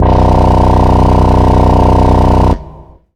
SYNTHBASS2-L.wav